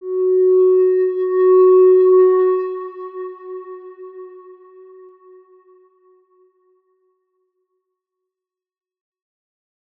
X_Windwistle-F#3-ff.wav